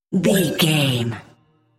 Sound Effects
Atonal
magical
mystical
special sound effects